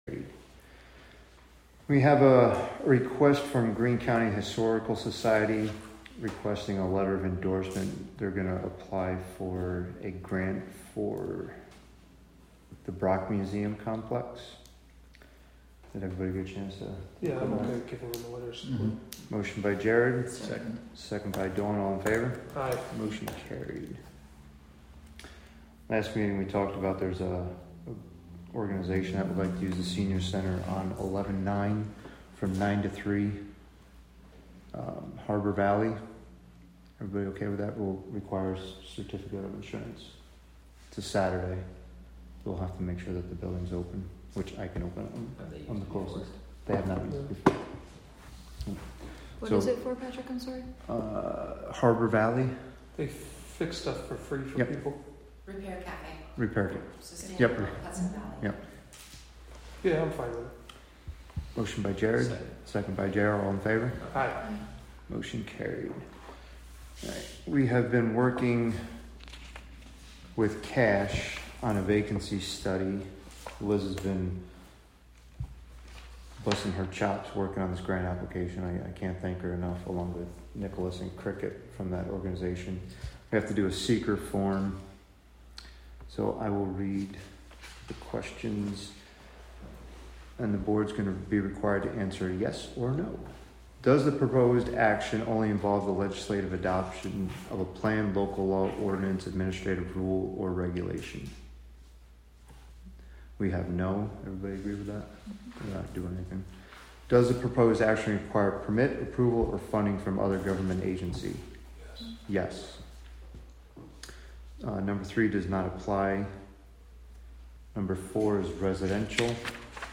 Live from the Town of Catskill: July 17, 2024 Catskill Town Board Meeting (Audio)